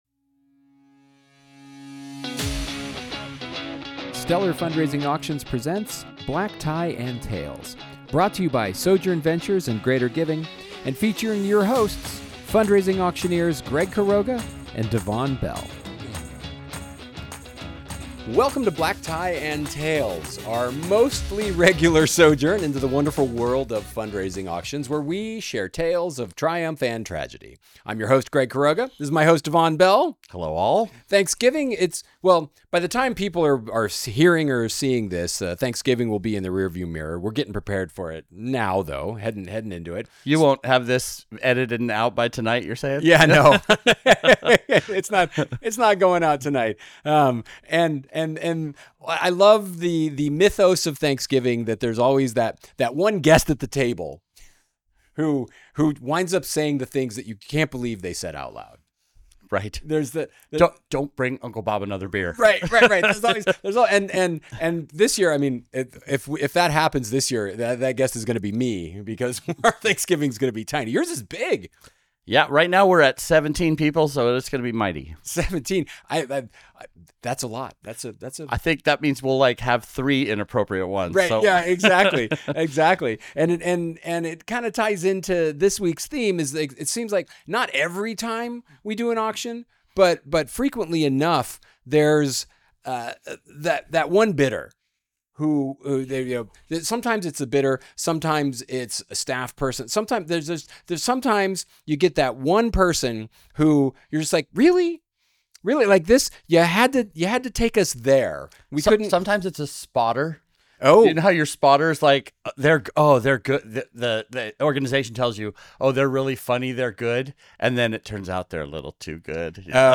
Take a peek behind the curtain of the wonderful world of fundraising auctions with two auctioneers who have seen it all.